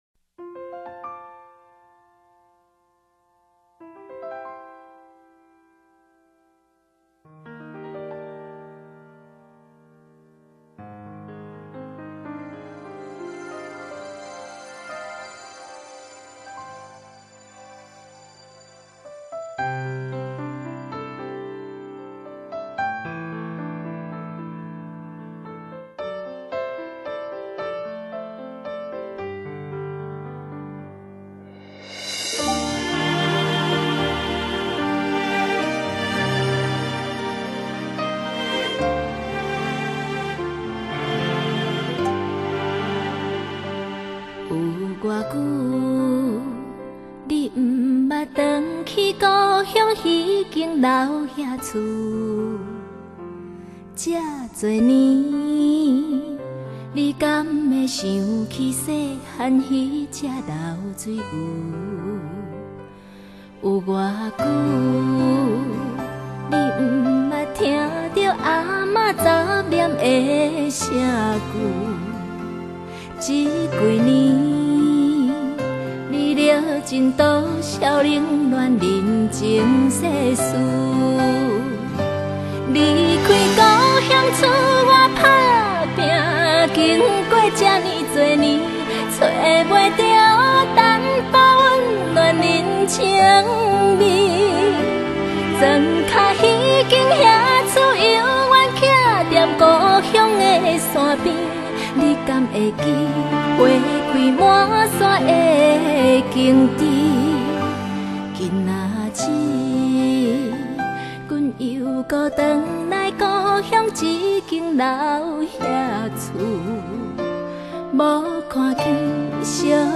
感人大合唱
動人情感滿溢全曲，交錯和聲繞樑恢弘